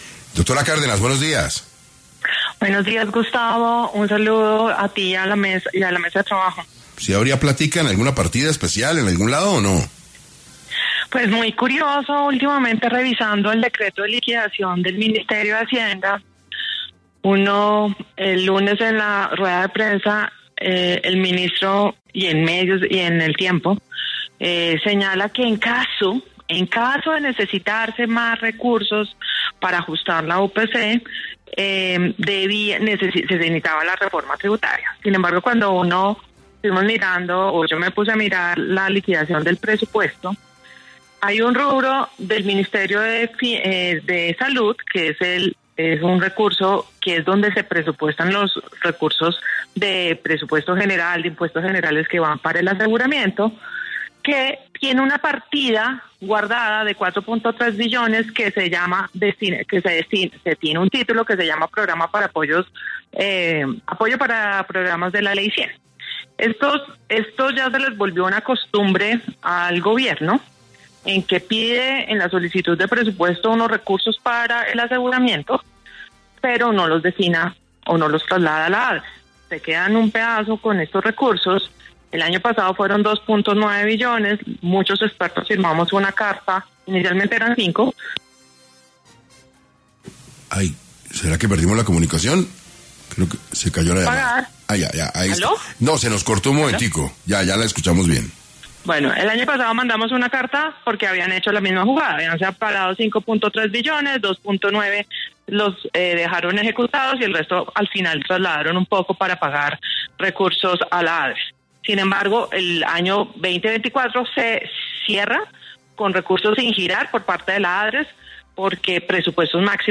Diana Cárdenas, exdirectora de la ADRES habló en 6AM sobre si hay recursos suficientes para aumentar el porcentaje de la UPC
Durante el programa de 6AM de este miércoles, 15 de enero, la exdirectora de la ADRES, Diana Cárdenas, se conectó para hablar sobre el aumento de la Unidad de Pago por Capacitación y si se cuenta con los recursos suficientes para hacerlo.